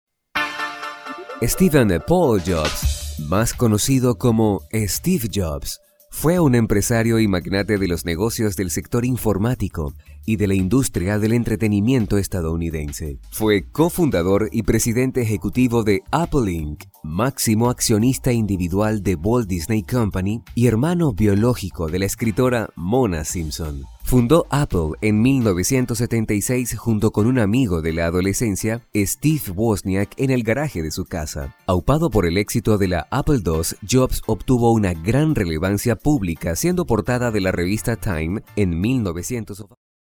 Sprechprobe: Werbung (Muttersprache):